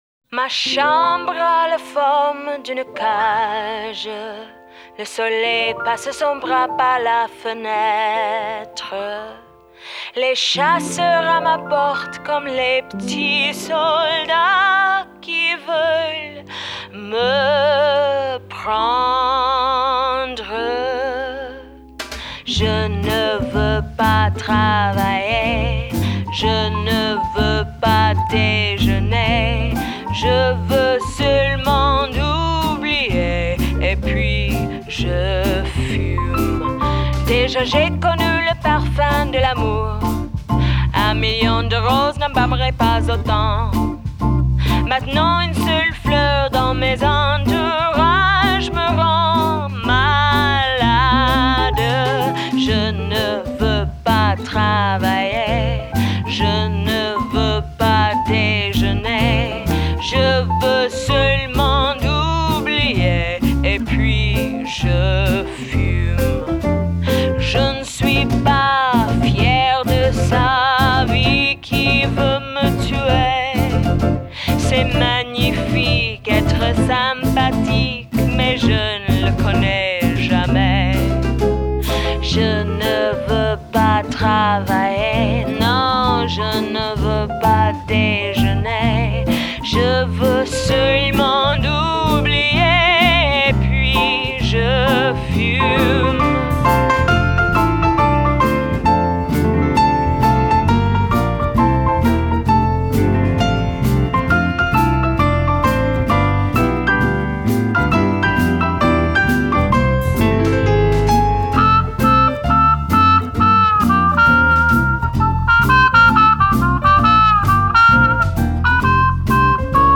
Genre: Jazz
這首曲子就是一派30年代的法式浪漫：老式麥克風效果＋一派輕鬆的吉他聲。這首法式香頌非常道地，連法國人都買單。
Recorded at Stiles Recording Studio in Portland, Oregon.